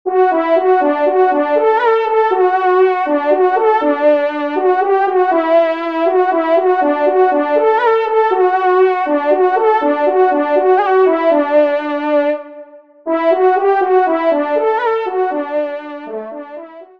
Fanfare de personnalité
Pupitre de Chant